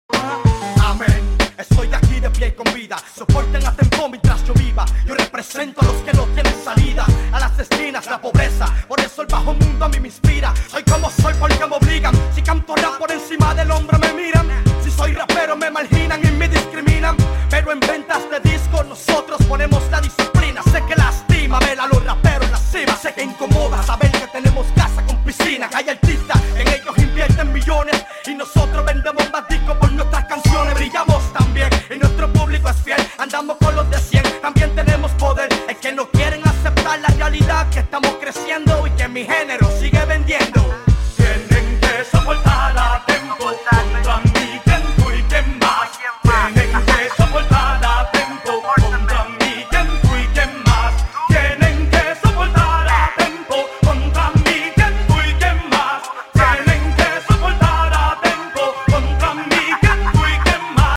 rap puerto riqueño de la vieja escuela